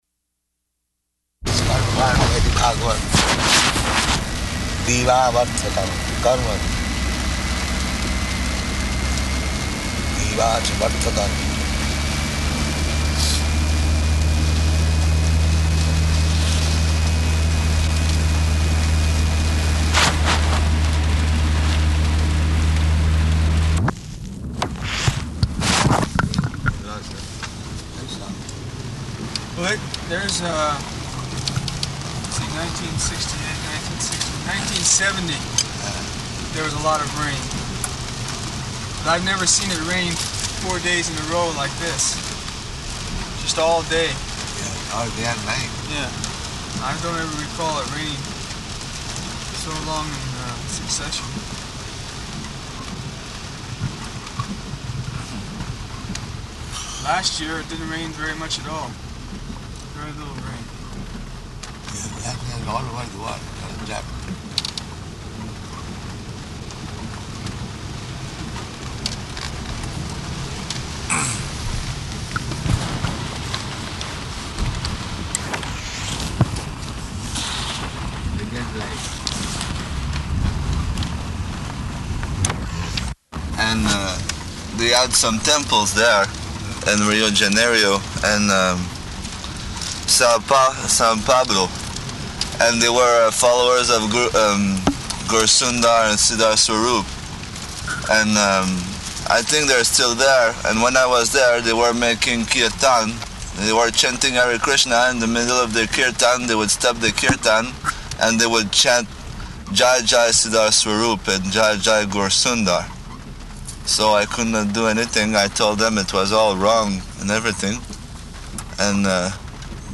Type: Walk
Location: Los Angeles